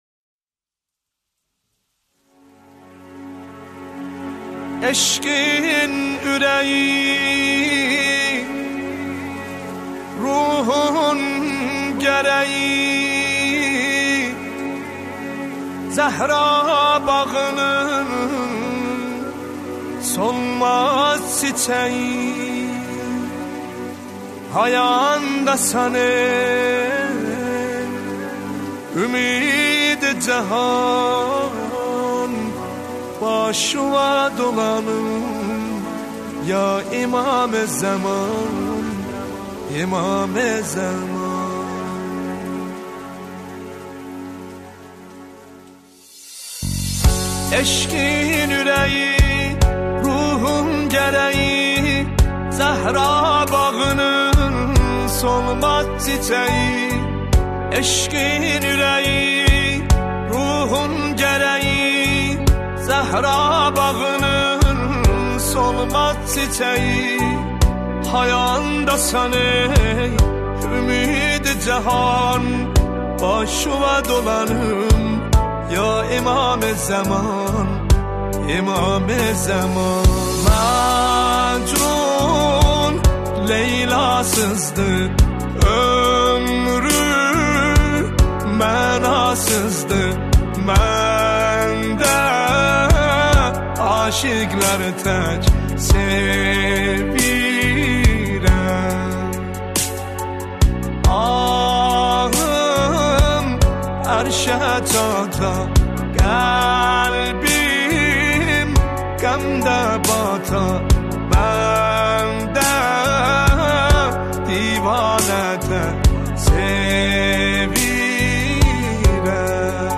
نماهنگ ترکی دلنشین و احساسی